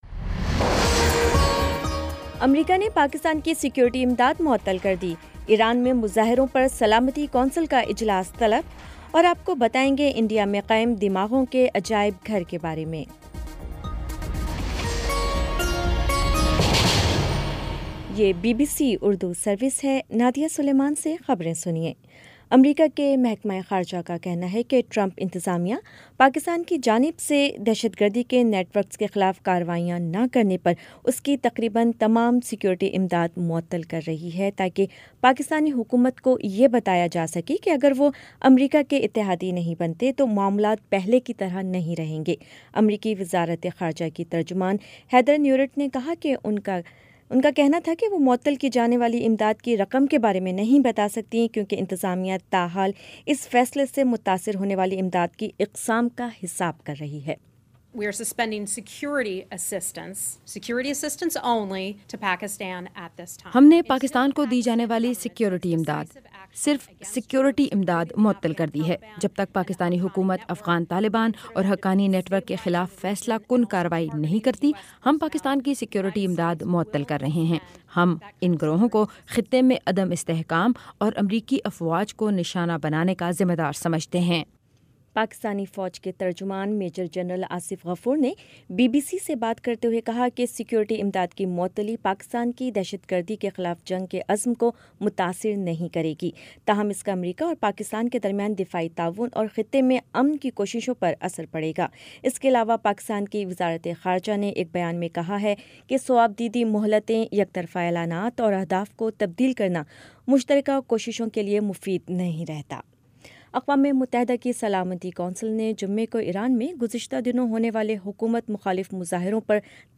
جنوری 05 : شام پانچ بجے کا نیوز بُلیٹن
دس منٹ کا نیوز بُلیٹن روزانہ پاکستانی وقت کے مطابق شام 5 بجے، 6 بجے اور پھر 7 بجے۔